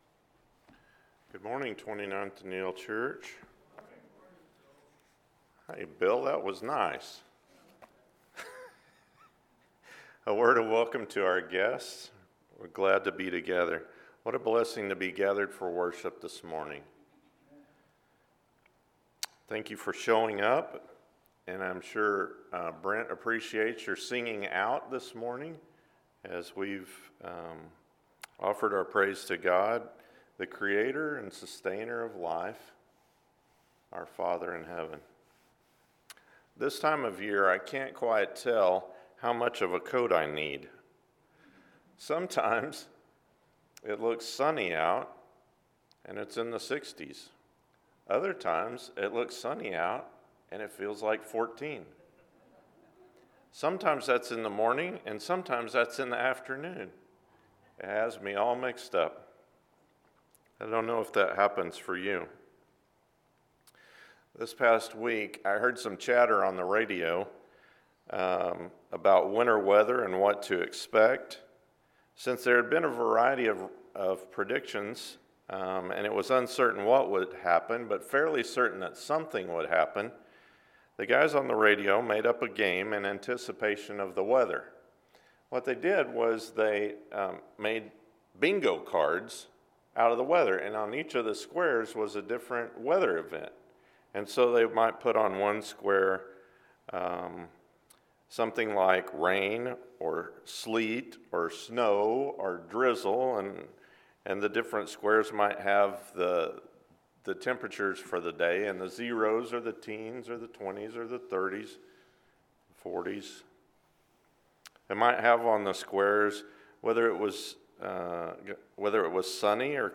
Therefore… – 1 Peter – Sermon